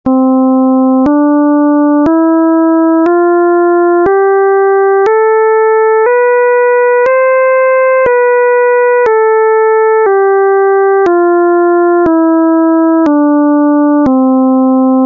Ἡ διάρκεια τοῦ κάθε φθόγγου εἶναι 1 δευτερόλεπτο.
Νη = 256Hz
Κλίμακα Νη-Νη'
Οἱ ἤχοι ἔχουν παραχθεῖ μὲ ὑπολογιστὴ μὲ ὑπέρθεση ἀρμονικῶν.